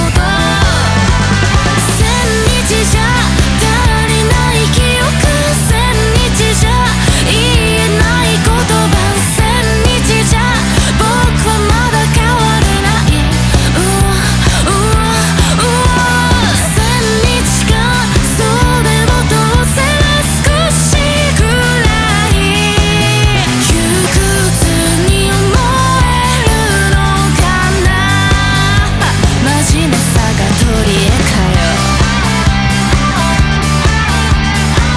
Жанр: Поп музыка / Рок
J-Pop, Rock